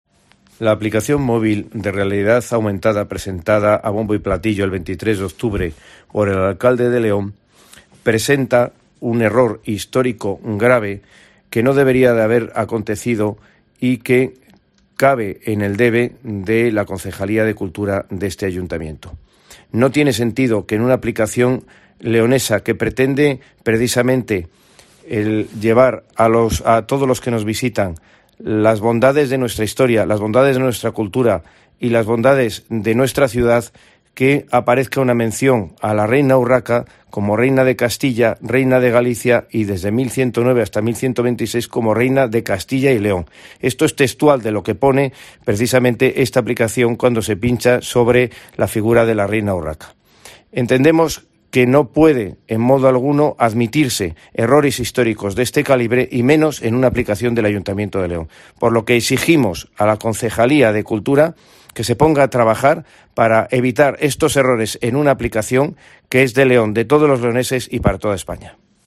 Eduardo López Sendino, portavoz UPL en el Ayuntamiento de León